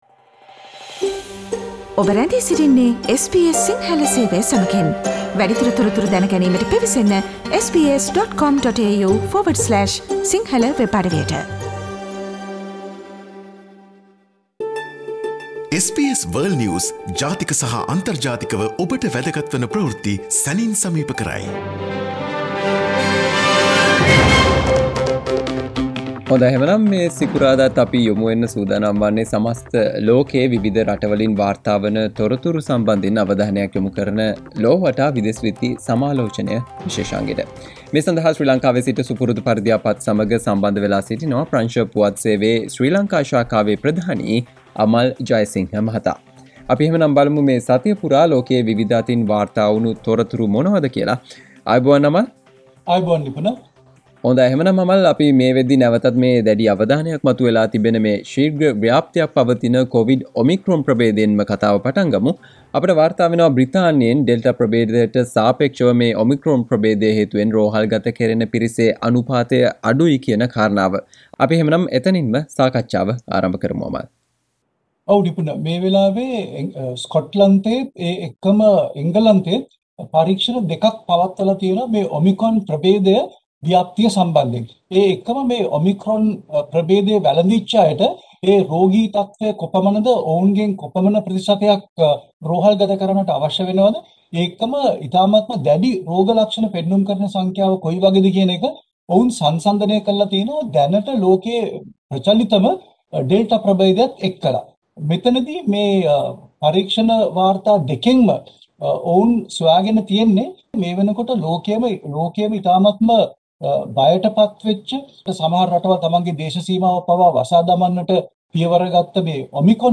වැඩසටහනට සවන්දිමෙට ඉහත ඇති පිංතූරය මධ්‍යයේ ඇති speaker සලකුණ මත ක්ලික් කරන්න SBS සිංහල සේවයේ සතියේ විදෙස් විත්ති සමාලෝචනය - "ලොව වටා" සෑම සිකුරාදා දිනකම ඔබ හමුවට.